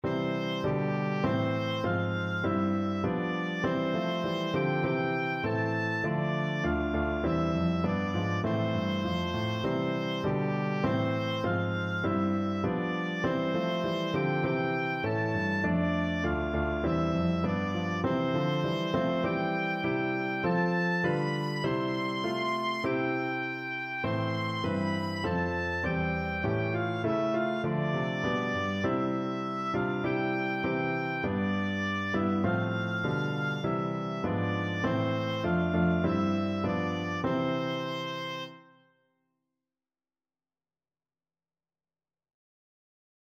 Oboe
Moderato
4/4 (View more 4/4 Music)
C major (Sounding Pitch) (View more C major Music for Oboe )
Traditional (View more Traditional Oboe Music)